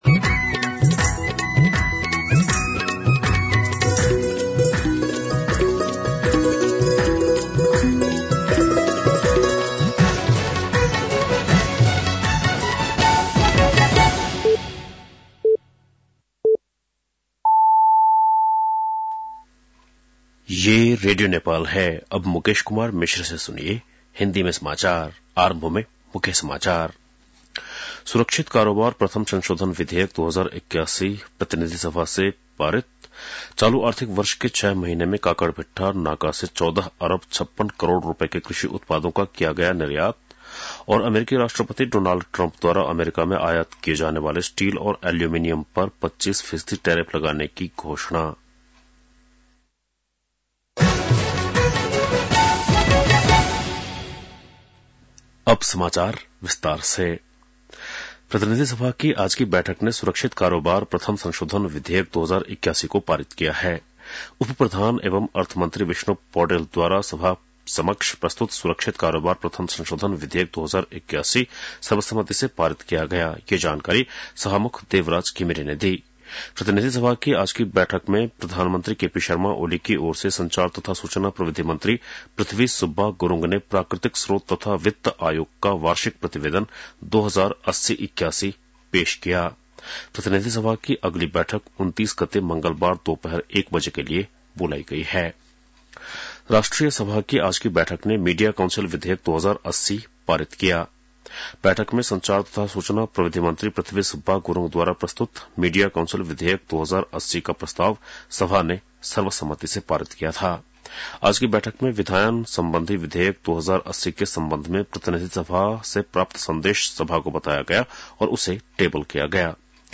बेलुकी १० बजेको हिन्दी समाचार : २९ माघ , २०८१